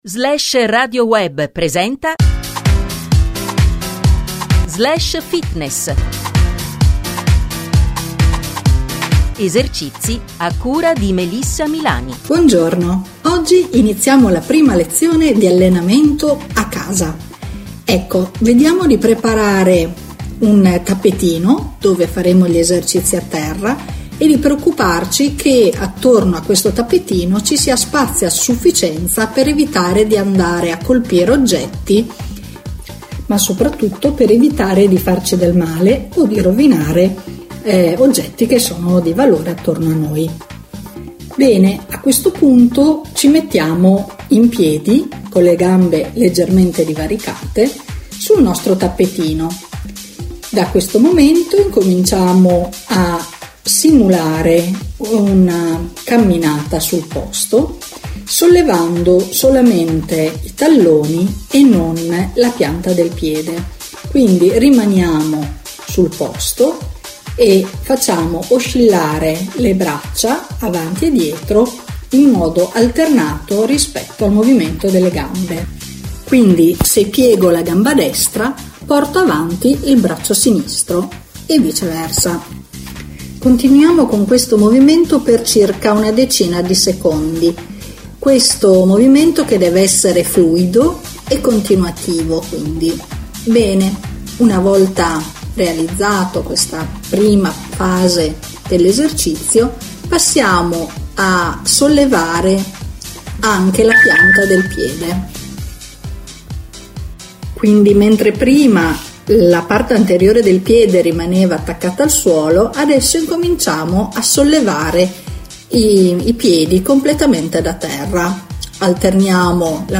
è possibile riascoltare la prima puntata di Slash Fitness, la nuova rubrica con le audiodescrizioni degli esercizi ginnici da svolgere in casa,andata in onda Lunedì 30 marzo su Slashradio Web.